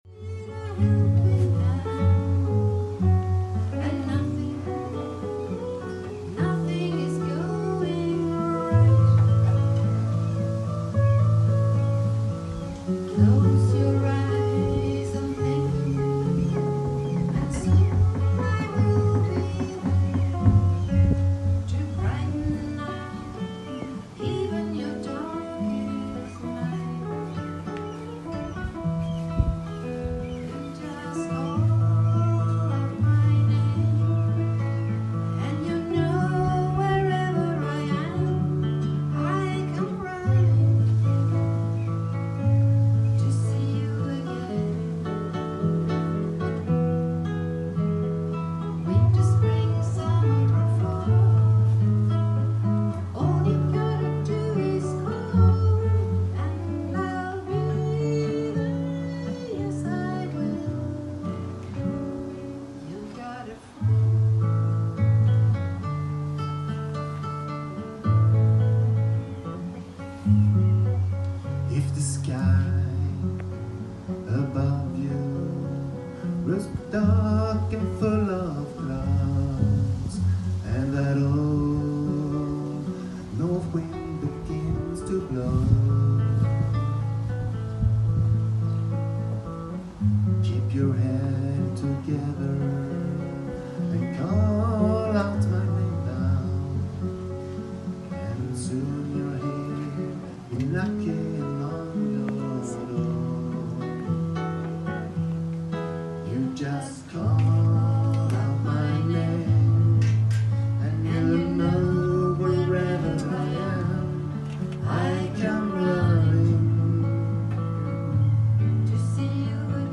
Mer sång och musik
Midsommarafton och gemensam knytislunch i paviljongen.
inför en trubadurstund